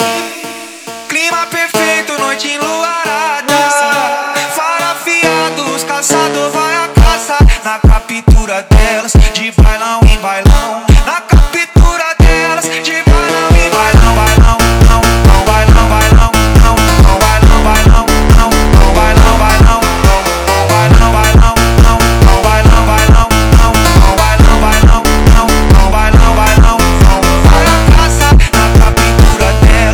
Модульные синтезаторы и глитчи трека
Жанр: Электроника